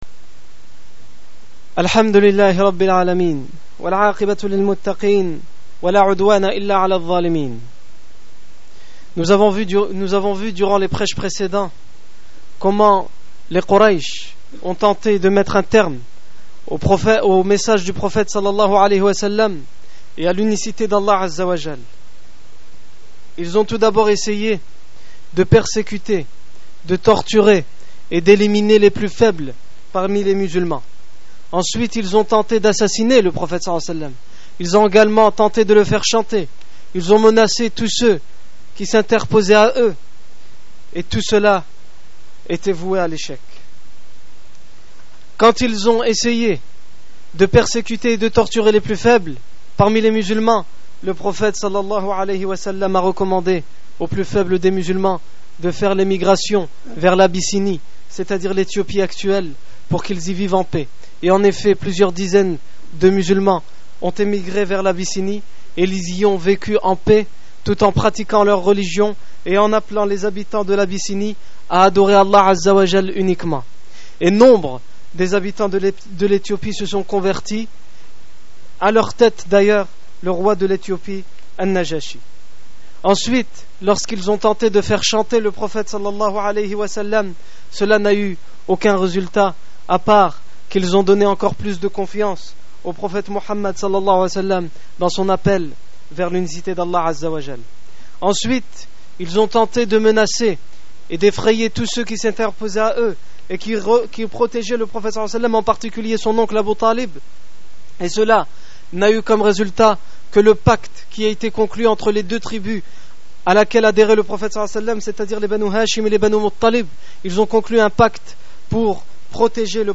Discours du 30 janvier 2009